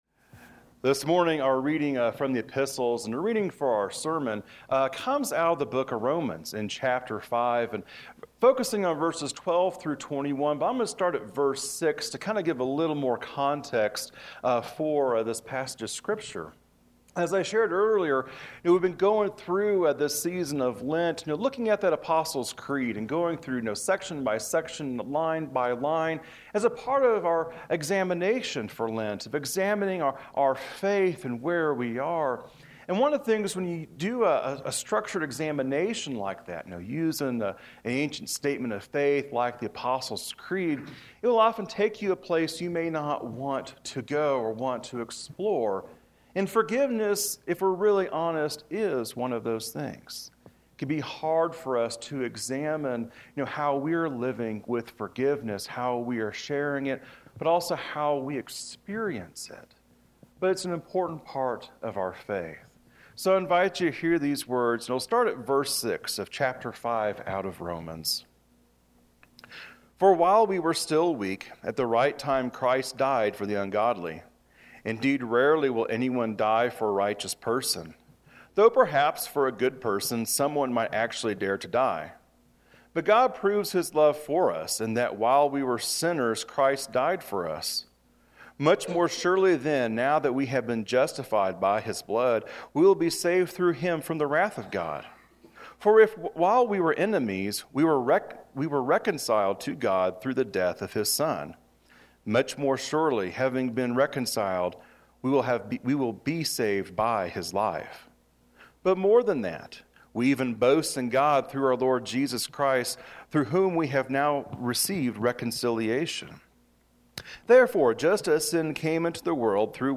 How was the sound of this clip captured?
This recording is from McClave UMC.